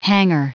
Prononciation du mot hangar en anglais (fichier audio)
Prononciation du mot : hangar